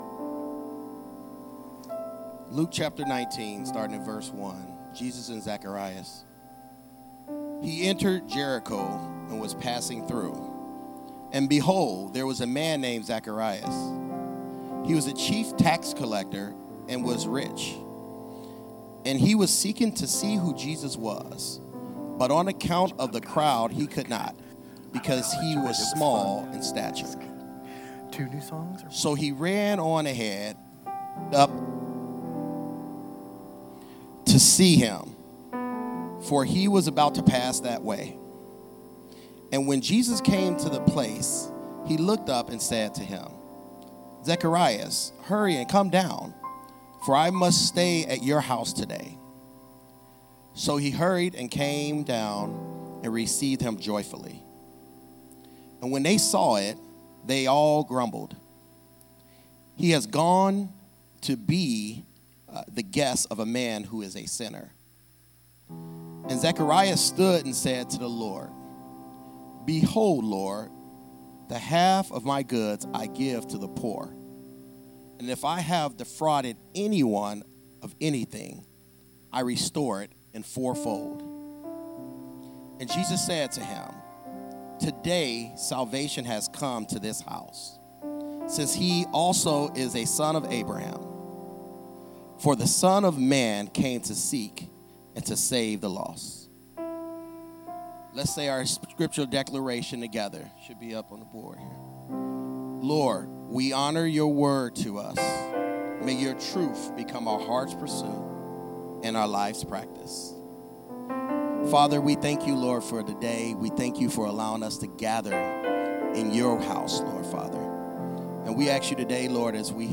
EHC & Shiloh Tabernacle Joint New Year’s Service